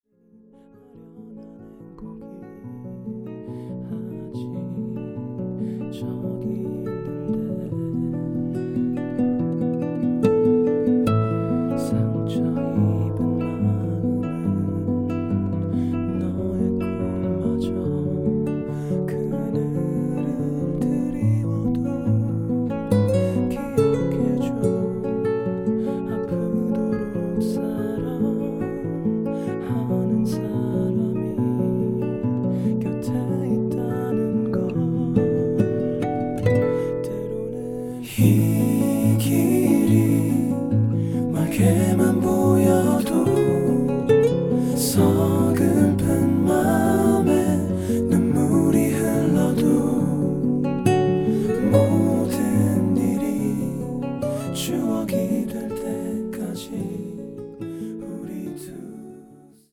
음정 원키 4:16
장르 가요 구분 Voice MR